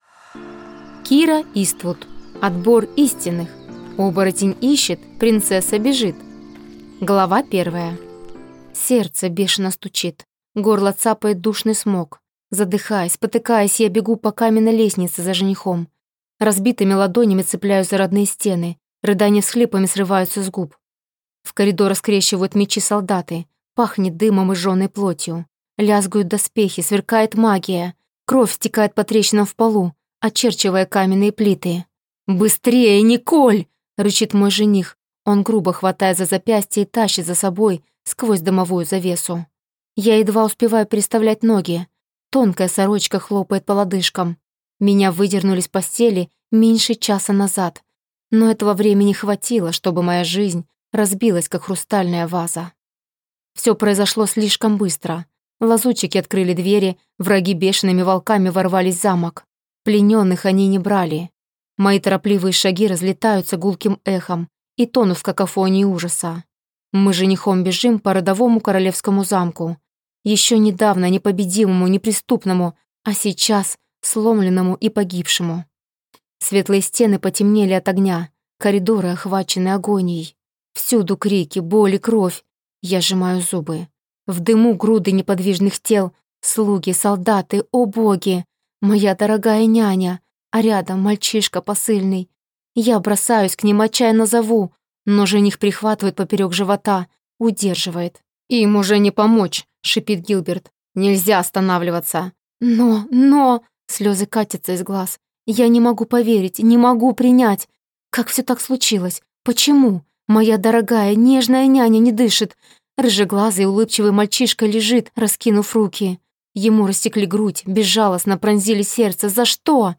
Аудиокнига Отбор истинных! Оборотень ищет, принцесса бежит | Библиотека аудиокниг